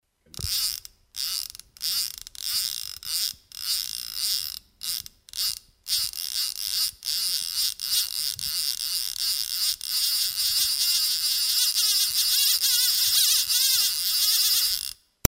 fliegenrolle4.mp3